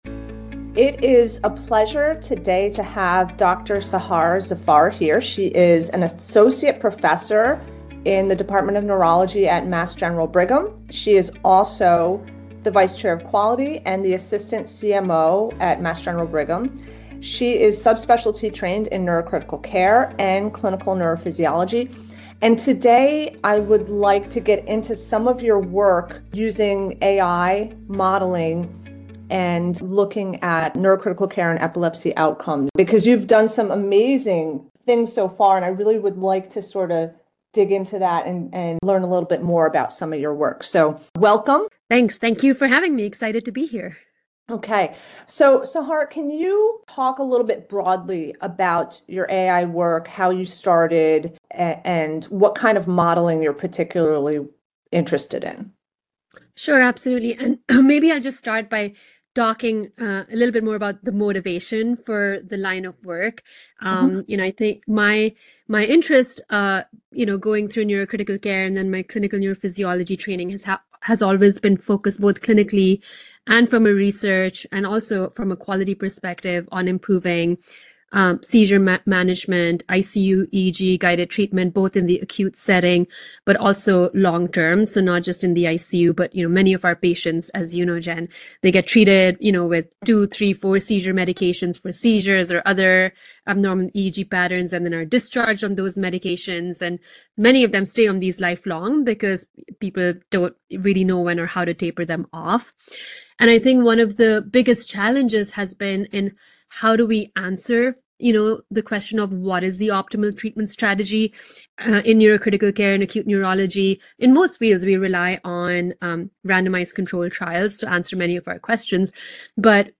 Episodes feature both solo insights and in-depth conversations with expert colleagues, with a shared focus